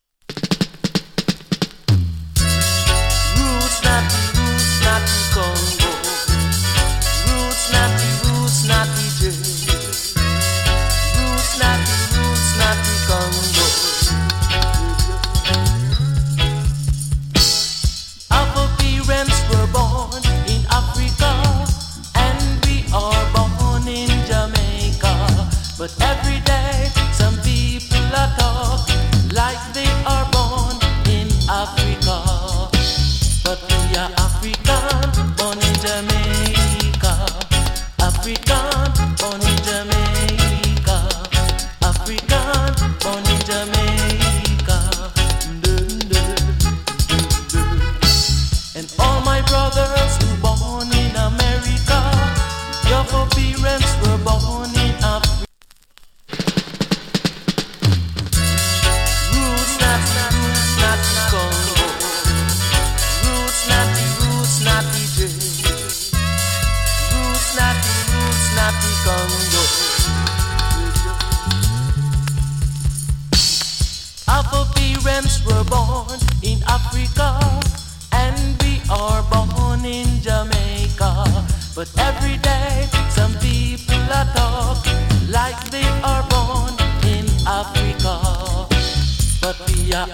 チリ、パチノイズ少々有り。
ノイズ少々有り。
77年 ROOTS ROCK FOUNDATION ! ＋ FINE DUB.